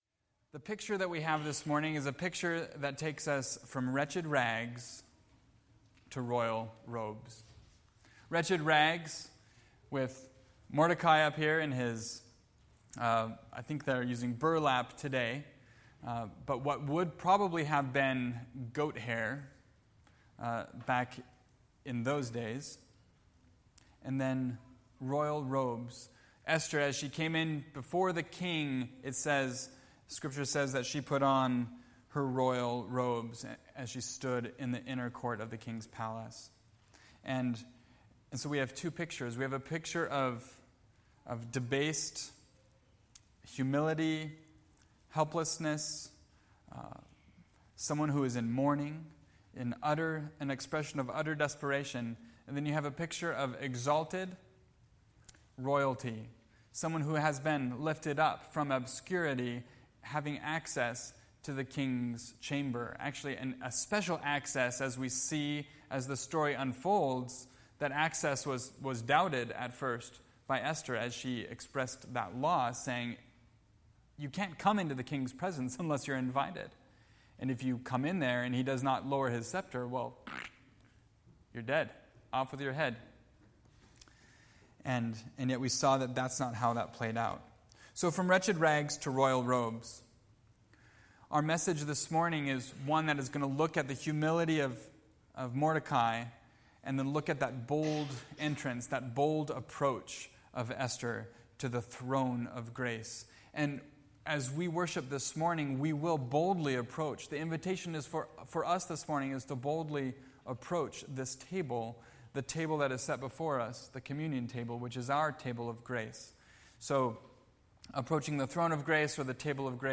Wretched Rags To Royal Robes (Esther 4:1 – 5:5) – Mountain View Baptist Church